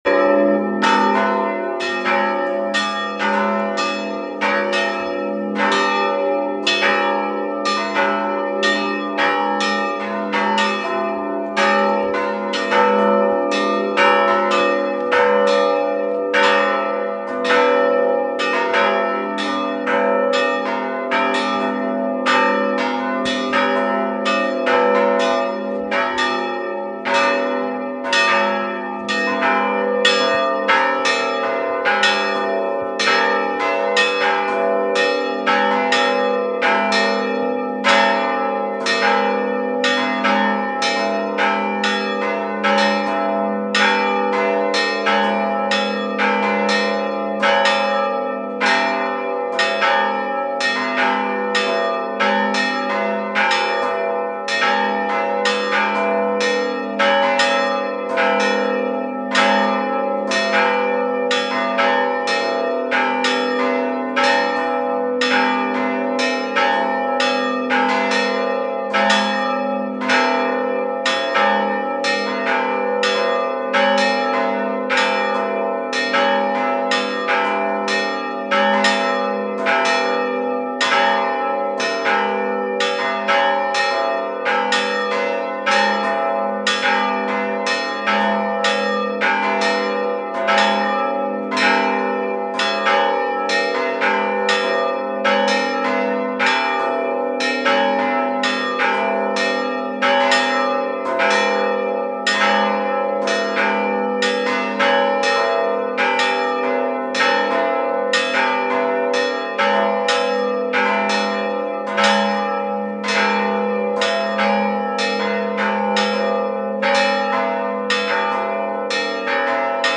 Das Glockengeläut in Mergeln erklingt seit Oktober 2025 in neuem Klang
Glockengelaeut2025.mp3